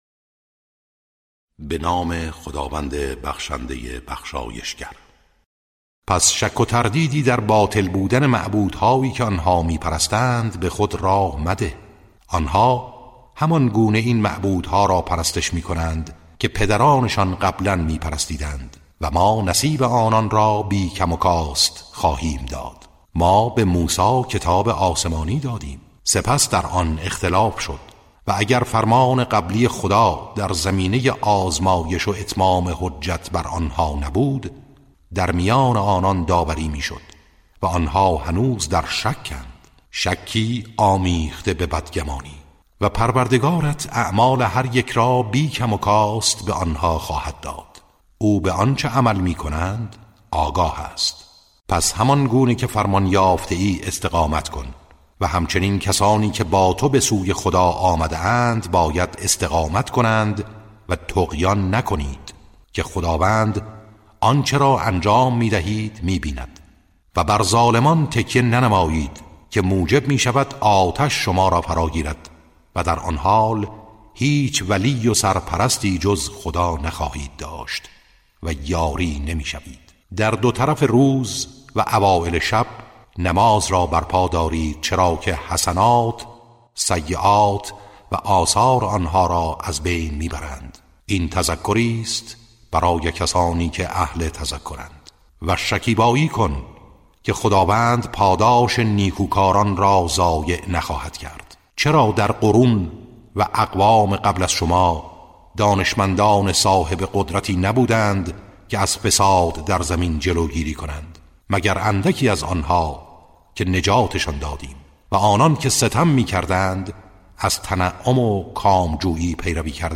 ترتیل صفحه ۲۳۴ سوره مبارکه هود (جزء دوازدهم)